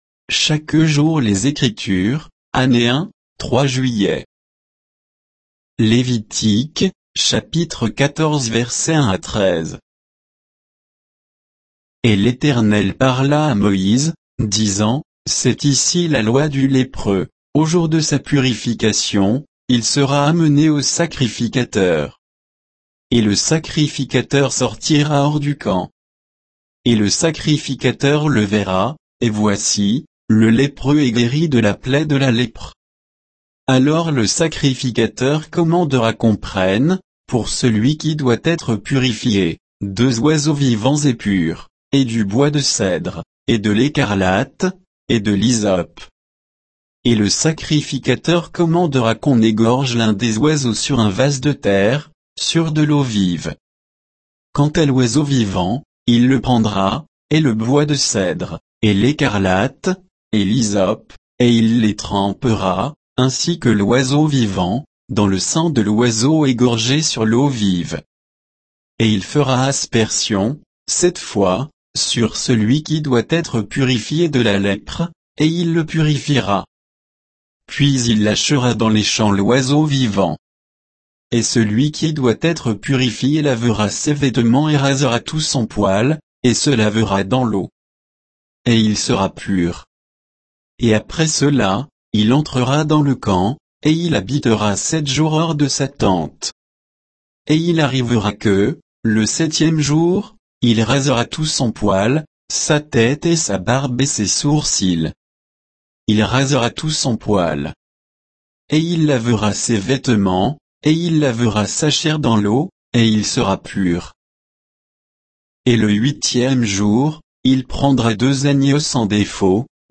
Méditation quoditienne de Chaque jour les Écritures sur Lévitique 14